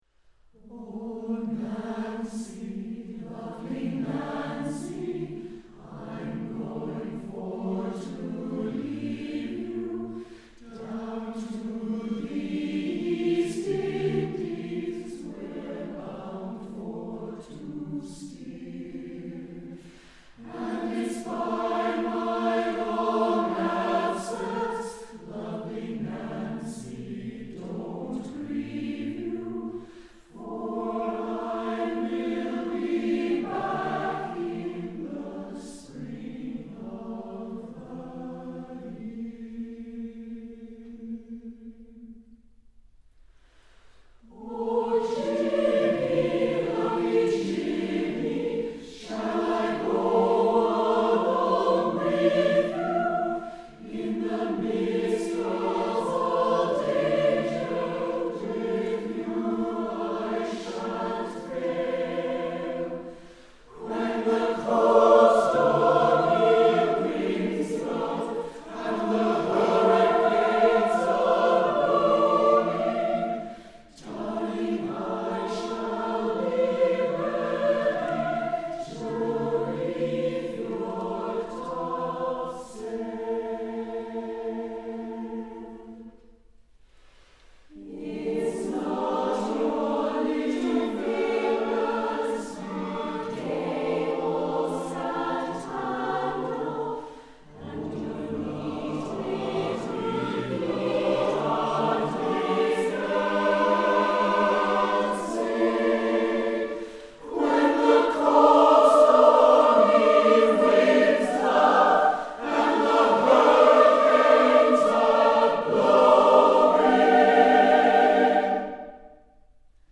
Voicing: S or Alto Solo, SATB [divisi]